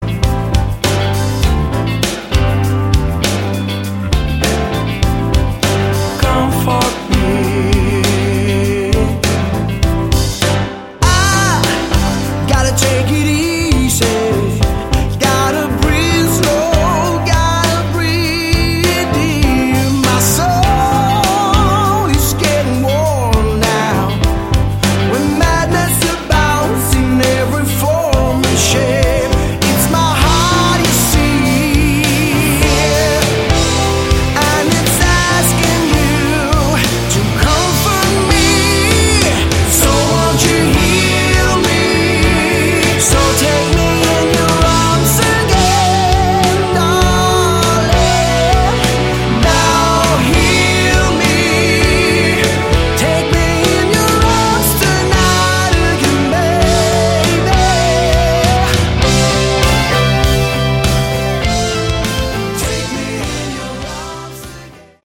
Category: AOR
Drums
Keyboards, Backing Vocals
Guitars, Backing Vocals
Lead Vocals
Bass, Backing Vocals
Very Melodic.